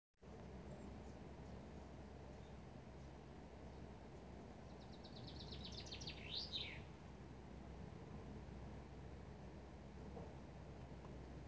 En uvanlig bokfink.